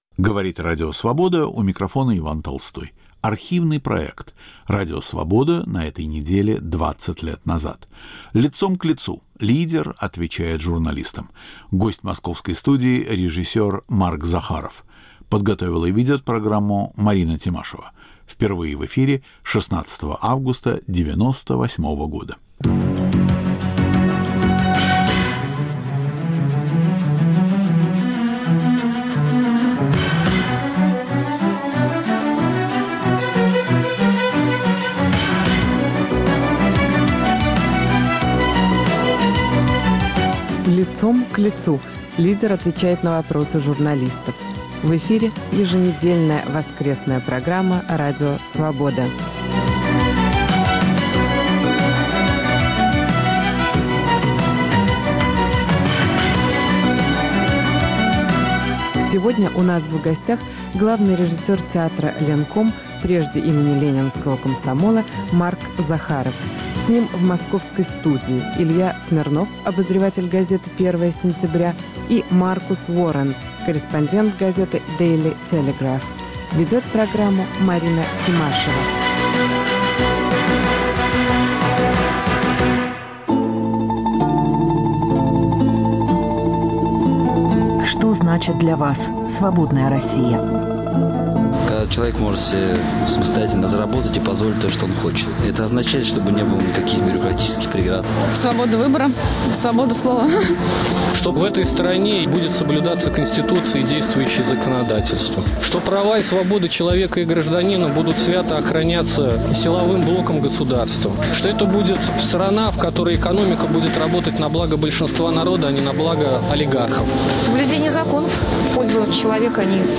Радио Свобода на этой неделе 20 лет назад. В нашей студии - режиссер Марк Захаров
Архивный проект.